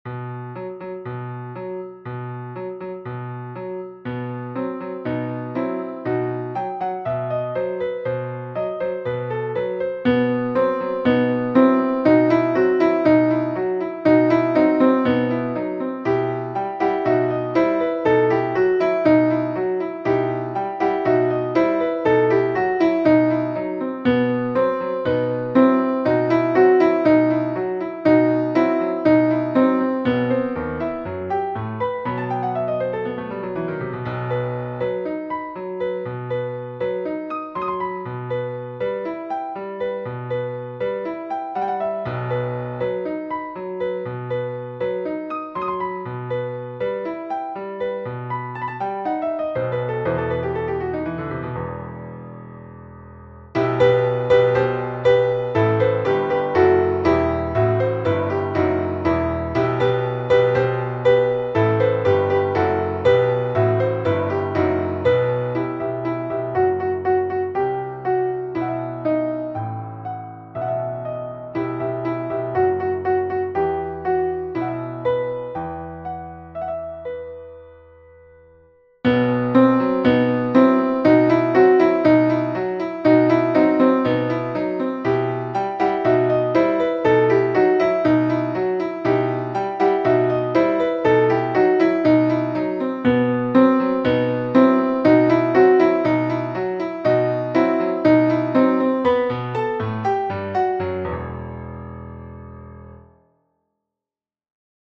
2-part treble choir and piano
世俗音樂